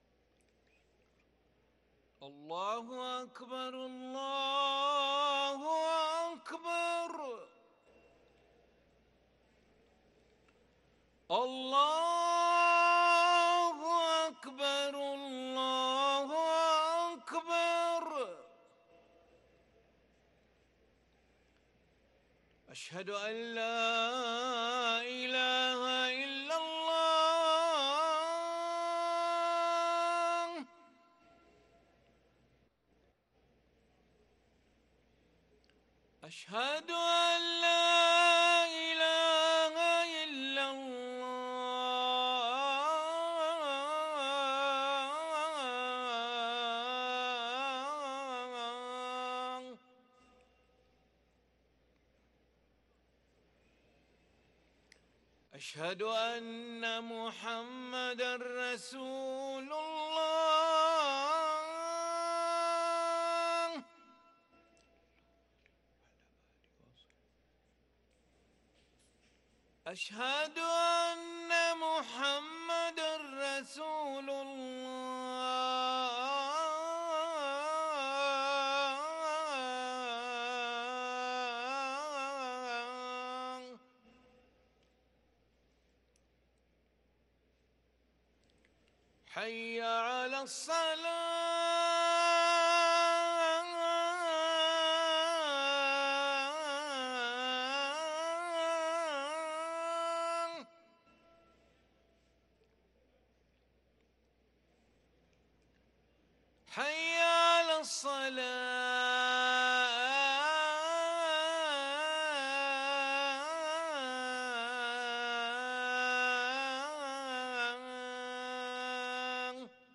أذان العشاء للمؤذن علي ملا الأحد 19 ربيع الآخر 1444هـ > ١٤٤٤ 🕋 > ركن الأذان 🕋 > المزيد - تلاوات الحرمين